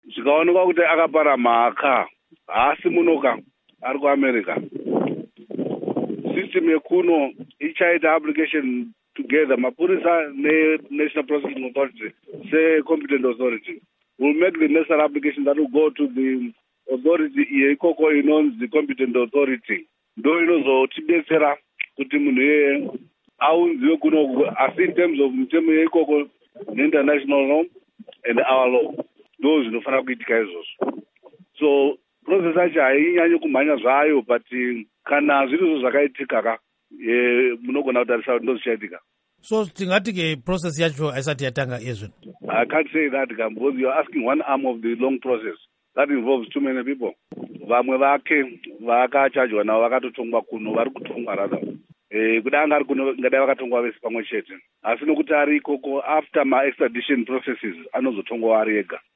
Hurukuro naVaJohannes Tomana